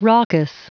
Prononciation du mot raucous en anglais (fichier audio)
Prononciation du mot : raucous